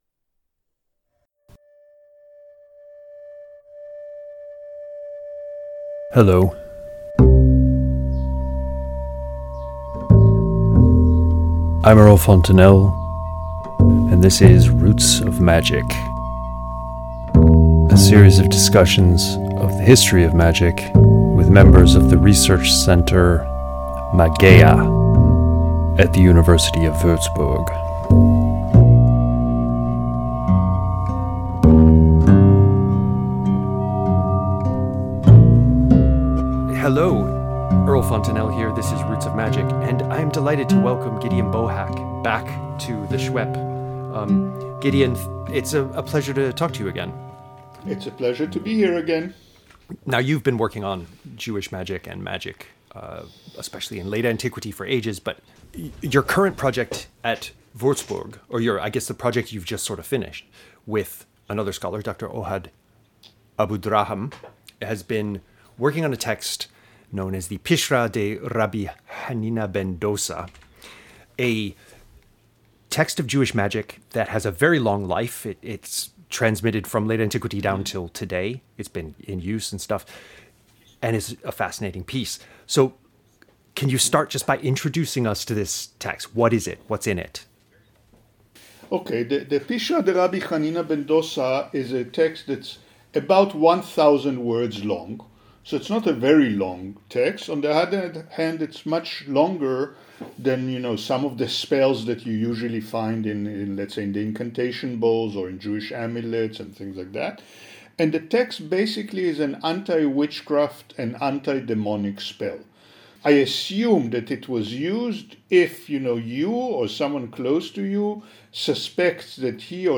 Roots of Magic Interview 7